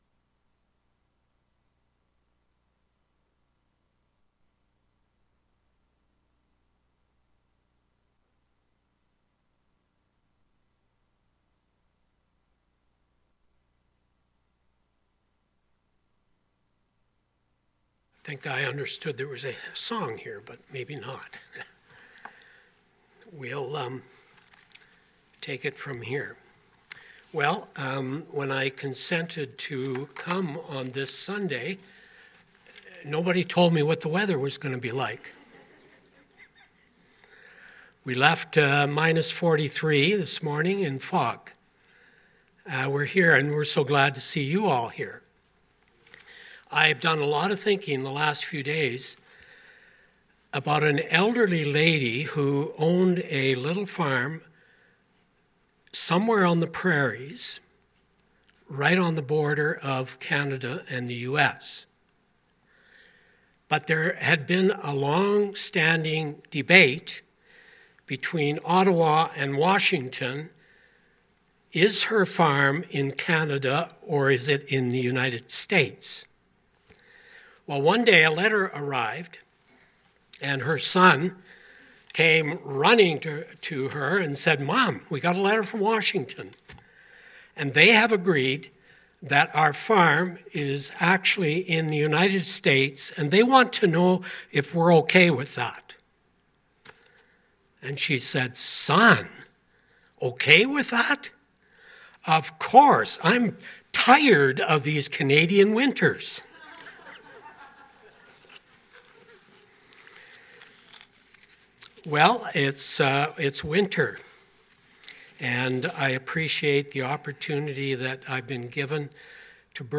2024 The Girl Nobody Wanted Preacher